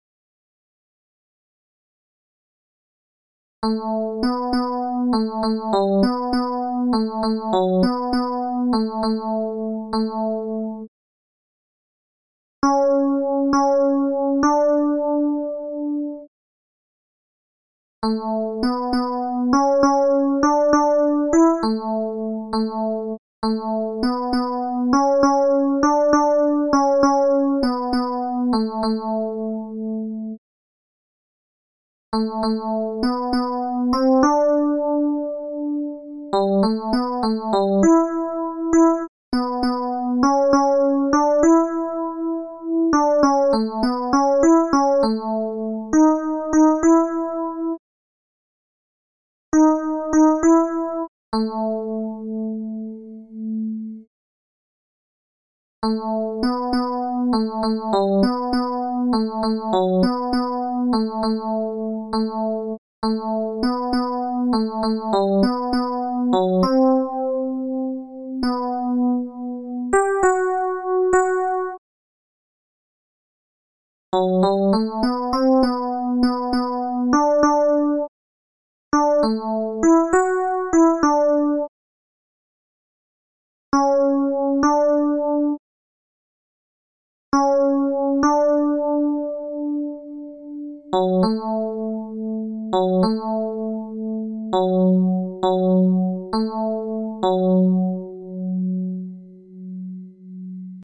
Ténors
Barcarolle_tenors.MP3